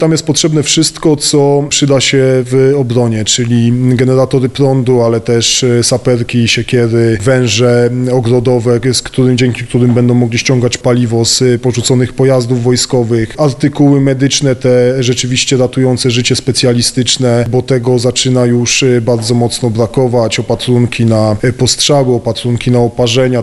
Mateusz Tyczyński, wiceprezydent Radomia zachęca inne miasta partnerskie oraz przedsiębiorców do przyłączenia się ponieważ potrzeb jest wiele: